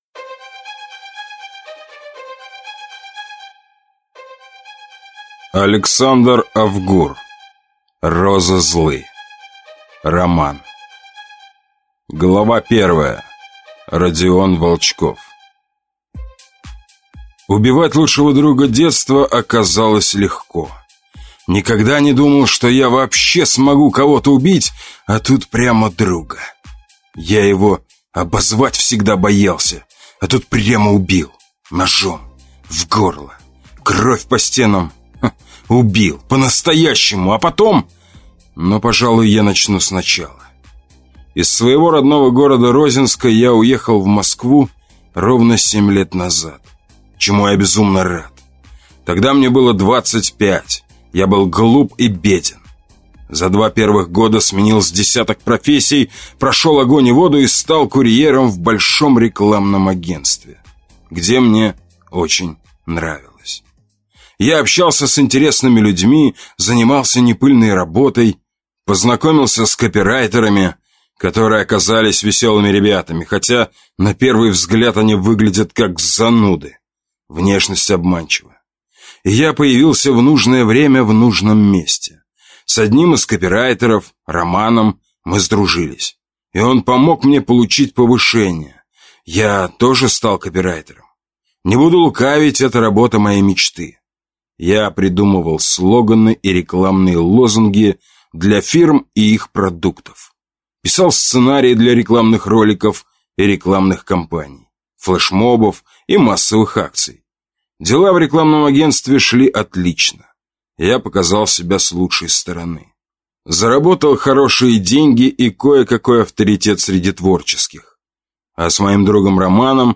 Аудиокнига Розы злы | Библиотека аудиокниг